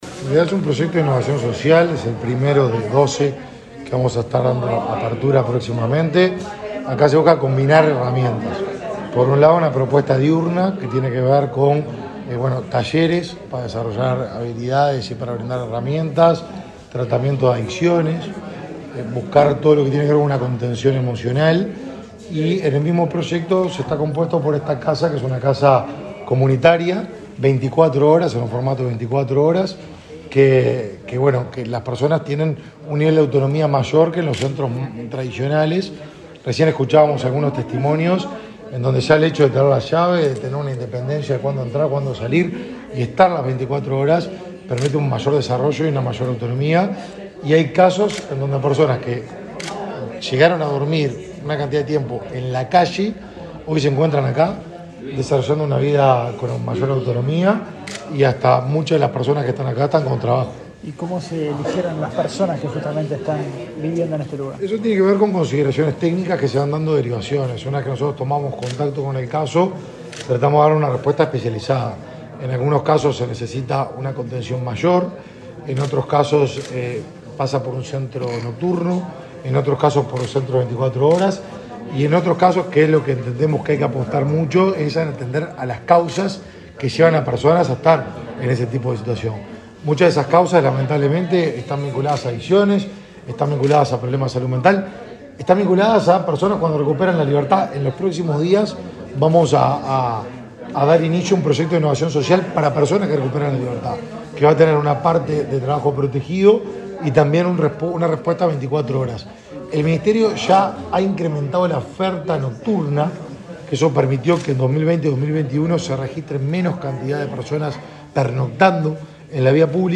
Declaraciones del ministro de Desarrollo Social, Martín Lema
El ministro de Desarrollo Social, Martín Lema, dialogó con la prensa, luego de visitar una casa comunitaria y un centro diurno para presentar el